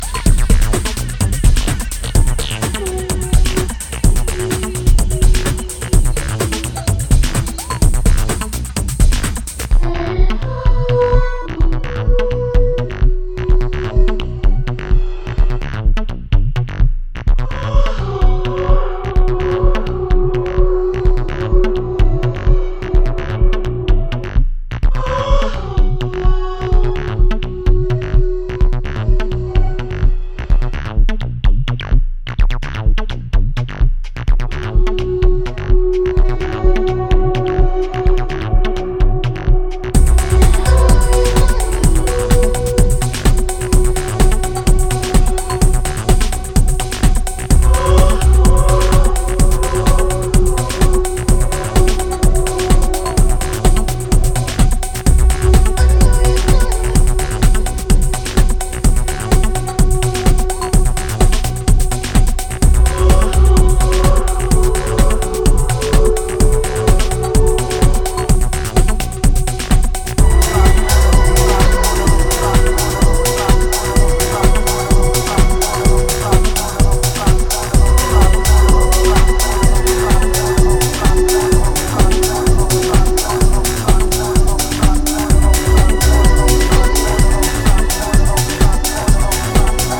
thunderous breaks, 303’s and serious sub-bass pressure
hip-hop, rave, and UK garage